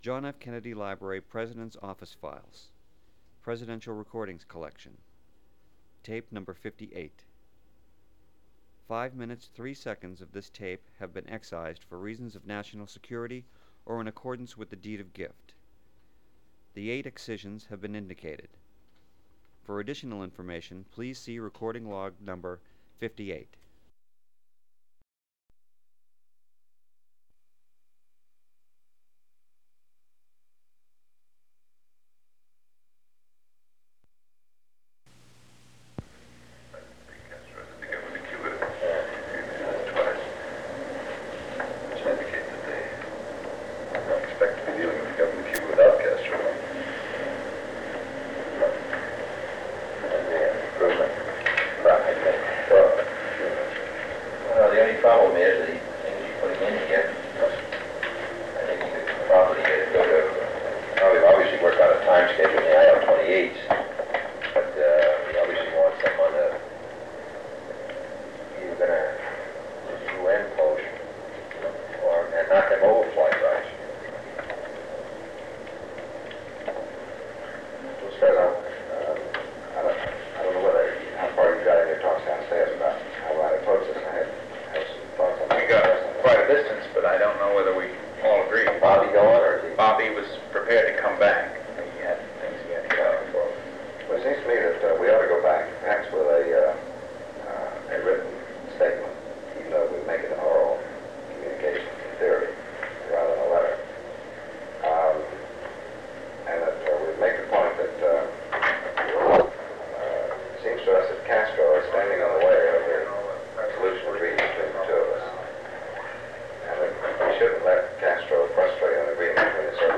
Informal Meeting on Cuba
Secret White House Tapes | John F. Kennedy Presidency Informal Meeting on Cuba Rewind 10 seconds Play/Pause Fast-forward 10 seconds 0:00 Download audio Previous Meetings: Tape 121/A57.